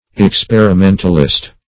Search Result for " experimentalist" : The Collaborative International Dictionary of English v.0.48: Experimentalist \Ex*per`i*men"tal*ist\, n. 1. One who makes experiments, especially one who likes to experiment; an experimenter.